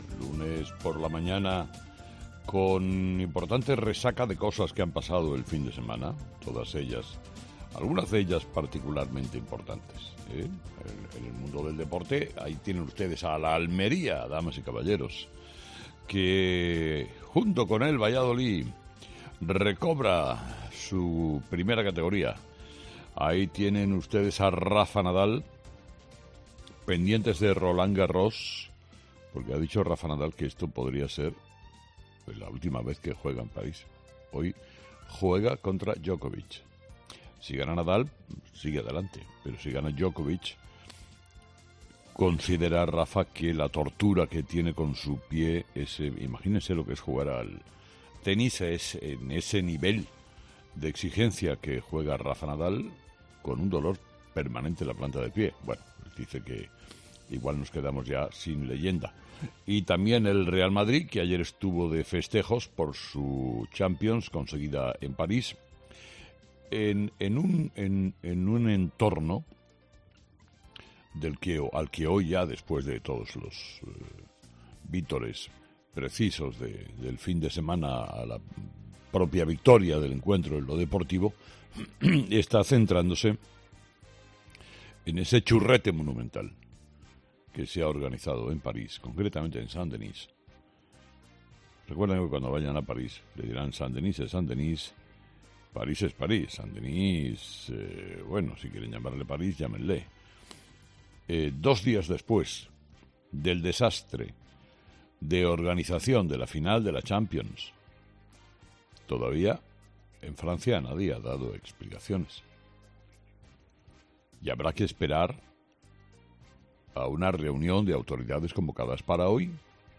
Carlos Herrera, director y presentador de 'Herrera en COPE', comenzó el programa del 30 de mayo analizando las principales claves de la jornada, que pasaban, entre otros asuntos, por lo ocurrido en la final de la Champions celebrada en París.